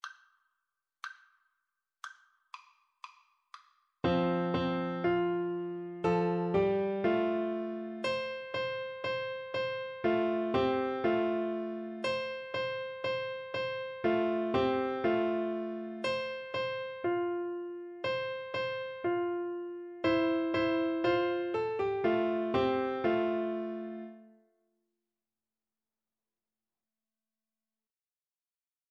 Free Sheet music for Piano Four Hands (Piano Duet)
F major (Sounding Pitch) (View more F major Music for Piano Duet )
Steadily =c.120